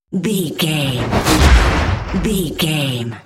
Dramatic chopper to hit 651
Sound Effects
Fast paced
In-crescendo
Atonal
dark
intense
tension
woosh to hit